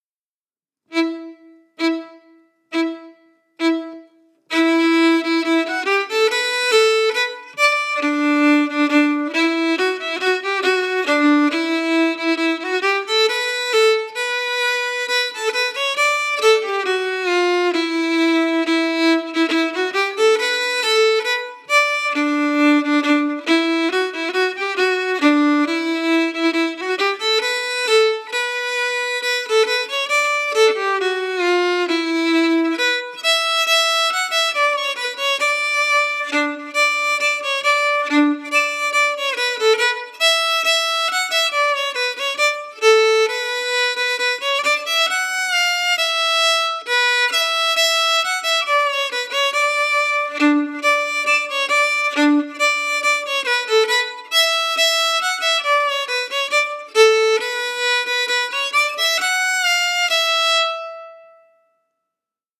Key: Em
Form: March
Played slowly for learning
Genre/Style: “Northern March”